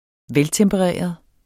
Udtale [ -tεmbəˈʁεˀʌð ]